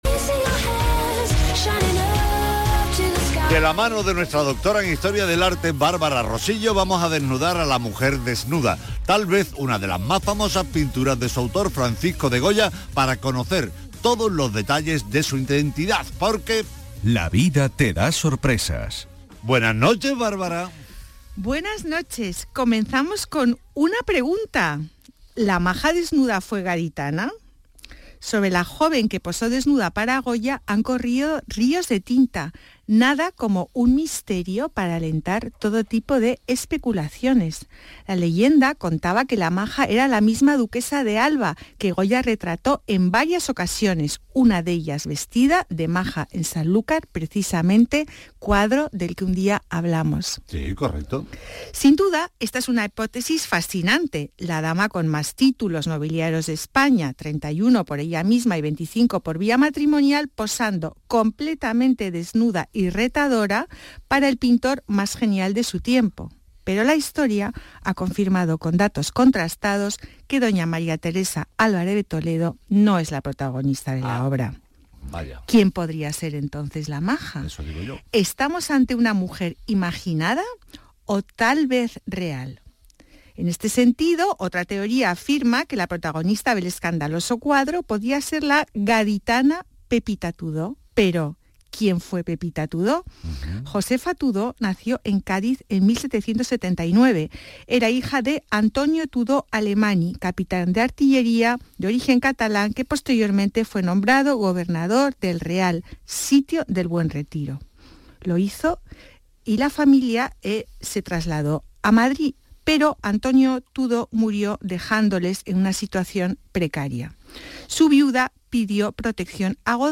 Aquí os dejo mi intervención en el programa de Radio Andalucía Información, «Patrimonio andaluz» del día 7/01/2024